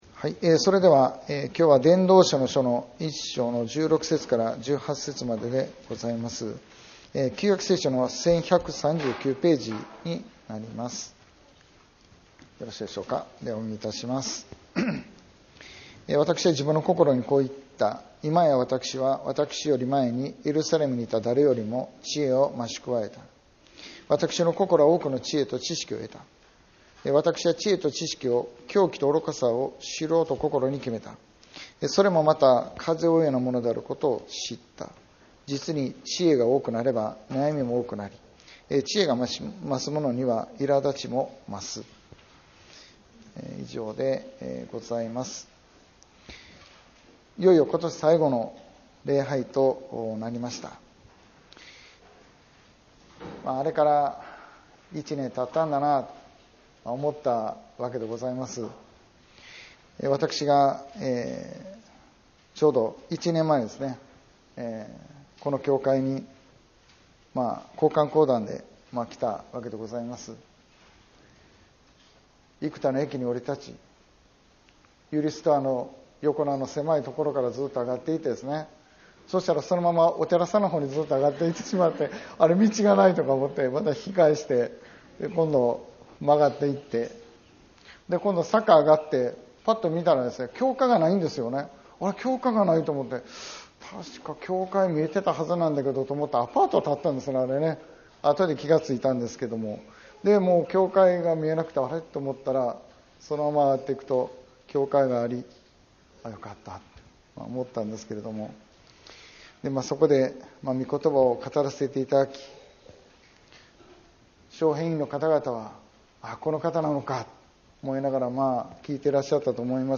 2025年12月28日 礼拝説教「知恵の限界」 | 生田丘の上キリスト教会